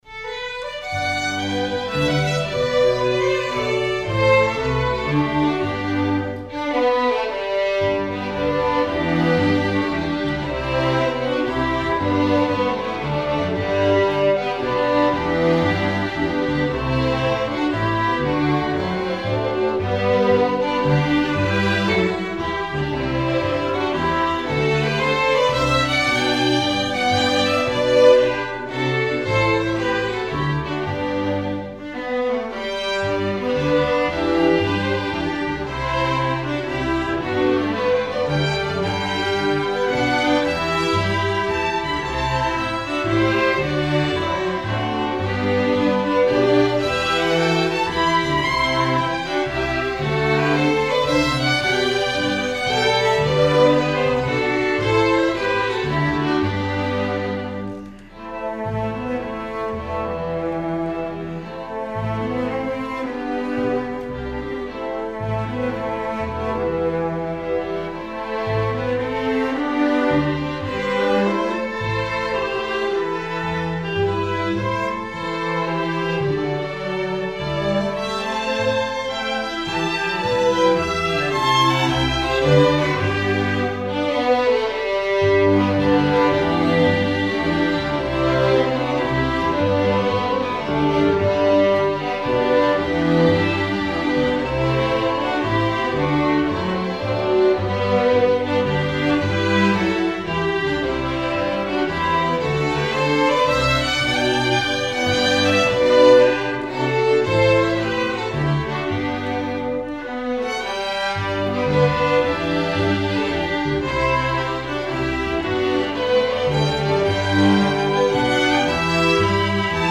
INTERMEDIATE, STRING QUARTET
country waltz
Notes: swing eighth notes, triplets, grace notes
Key: G major/ A minor